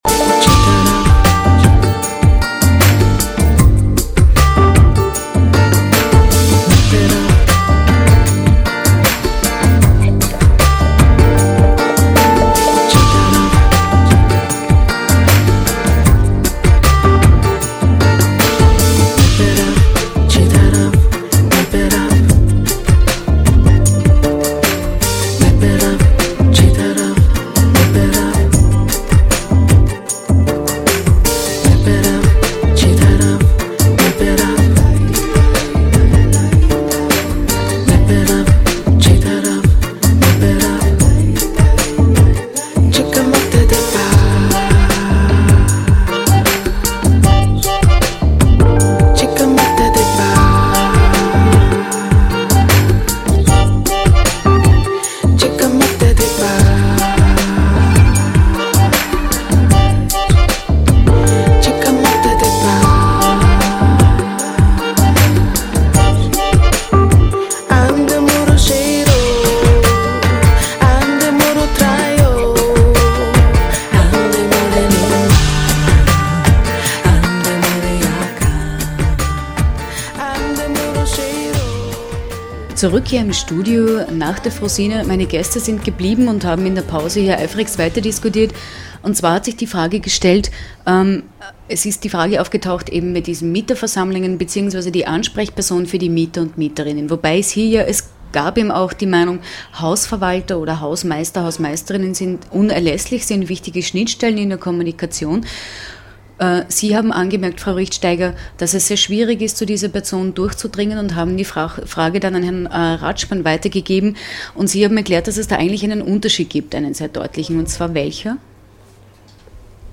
In dieser Sendung geht es um partizipative Stadtteilentwicklung und wie diese aussehen kann, speziell in einem so genannten "Randdisktrikt" wie Auwiesen. Dazu haben wir ExpertInnen und AnwohnerInnen live im Studio.